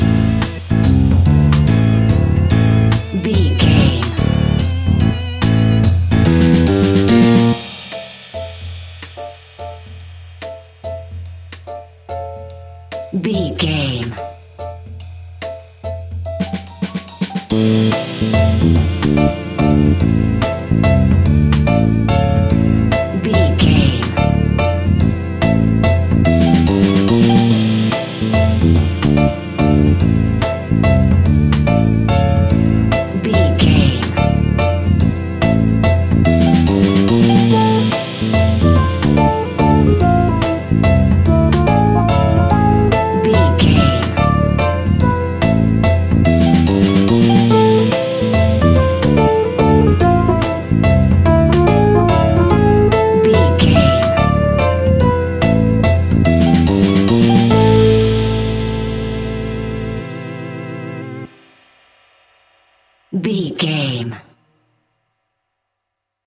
Aeolian/Minor
scary
ominous
playful
electric piano
drums
bass guitar
synthesiser
spooky
horror music